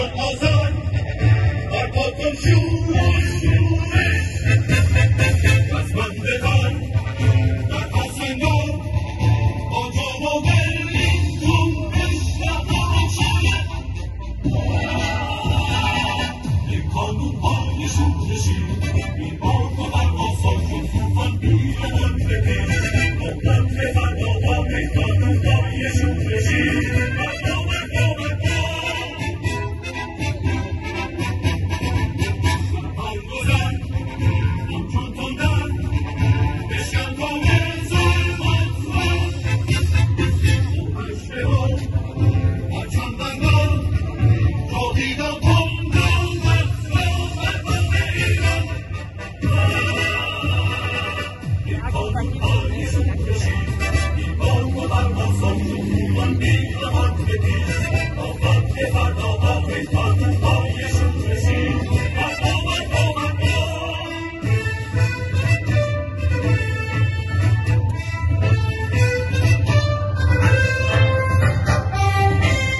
تظاهرات یاران شورشگر ونکور در حمایت از قیام مردم دلیر سراوان و محکومیت کشتار انان
یاران شورشگر ونکور روز شنبه 27 فوریه تظاهراتی در حمایت از قیام مردم دلیر بلوچستان و محکومیت قتل سوختبران برگزار کردند